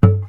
strings_muted
mute-04.wav